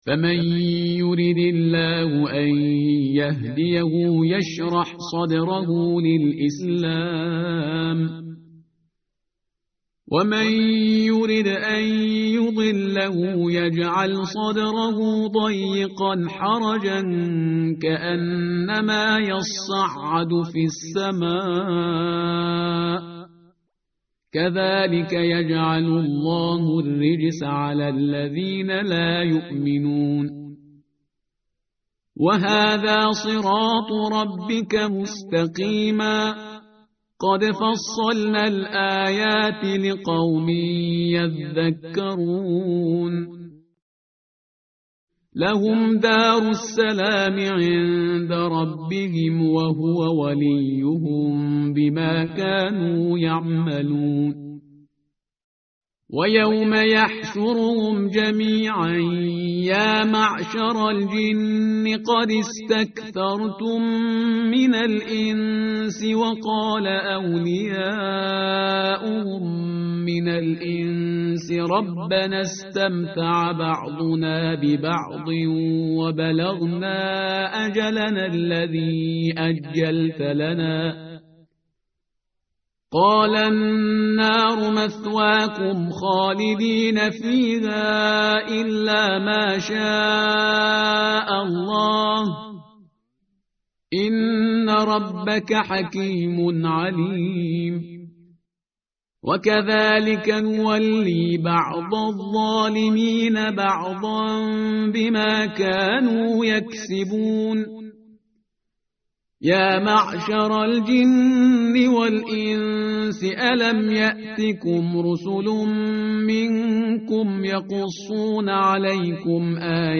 tartil_parhizgar_page_144.mp3